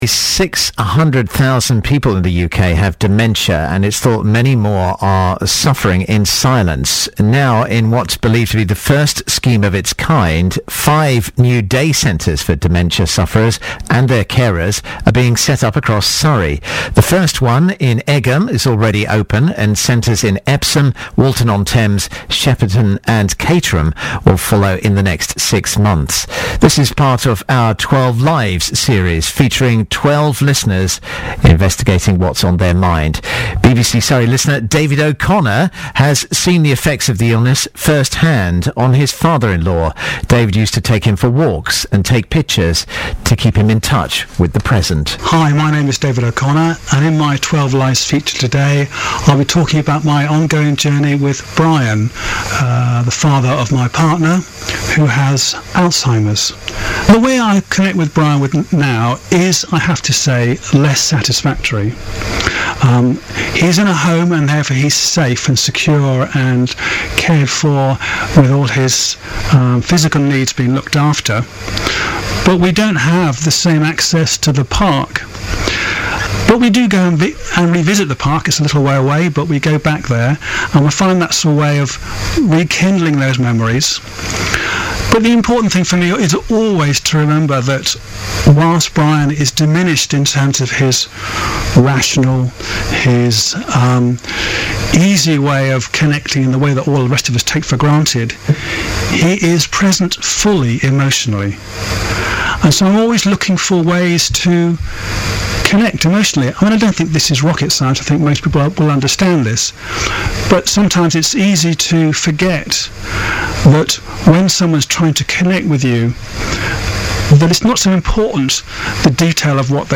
BBC Surrey interview about new advice centres for people with dementia